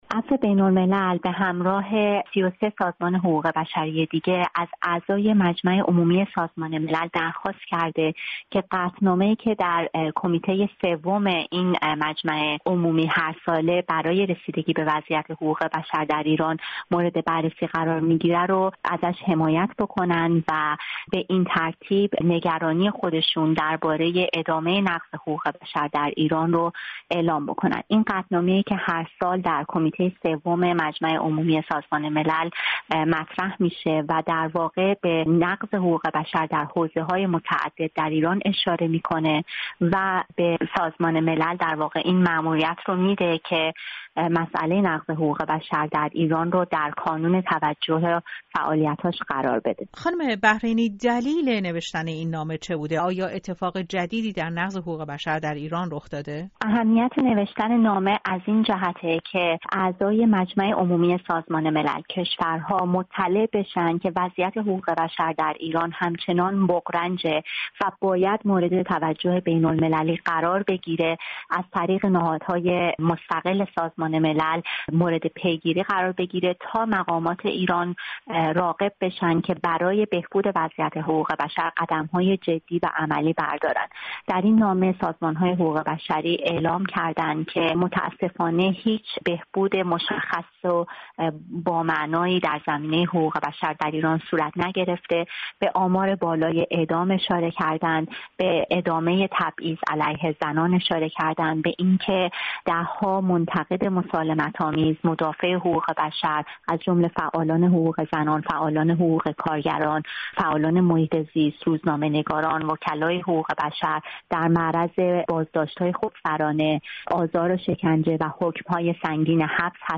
۳۴ سازمان‌ه غیردولتی با اشاره به قوانین و سیاست‌های ایران که به تداوم نقض حقوق بشر انجامیده است، از جامعه بین‌الملل خواسته‌اند تا ایران را برای پایان دادن به موارد نقض حقوق بشر زیر فشار بگذارند و مصرانه از تهران بخواهند به تعهداتش در این زمینه پایبند باشد. گفت‌وگو